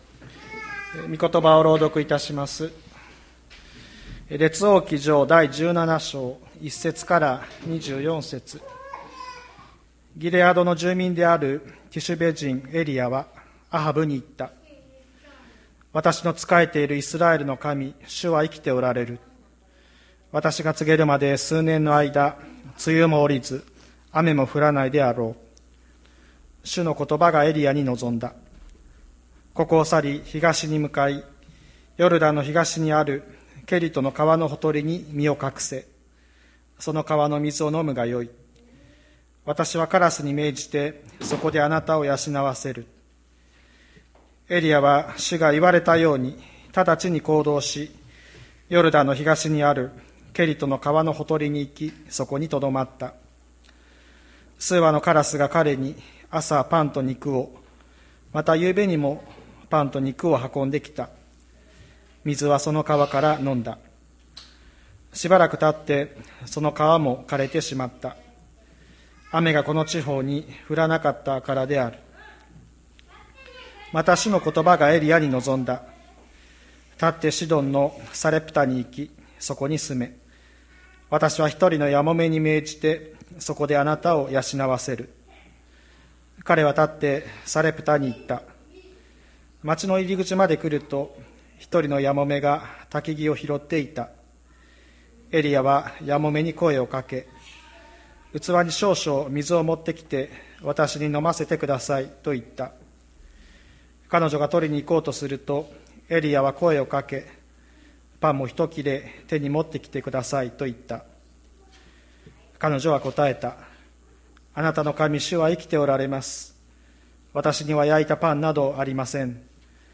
2021年06月06日朝の礼拝「主の言葉は真実です」吹田市千里山のキリスト教会
千里山教会 2021年06月06日の礼拝メッセージ。